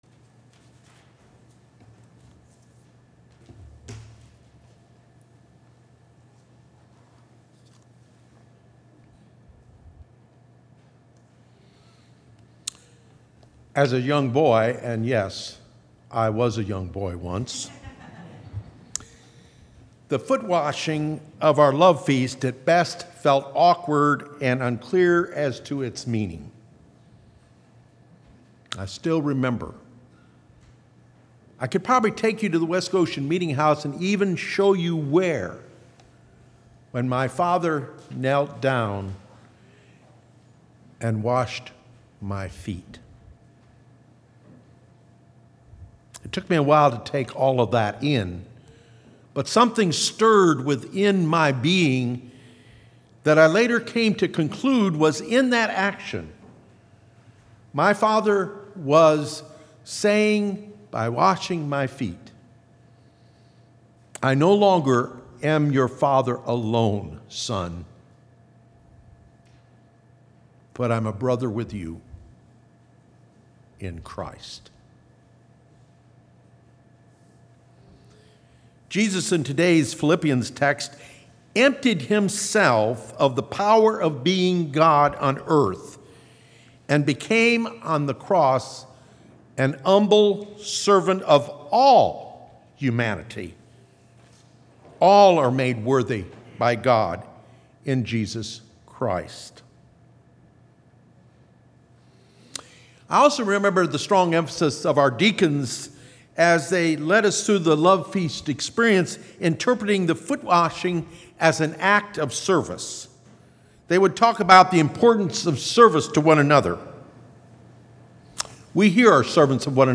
Bible Text: Matthew 20:20-28 | Preacher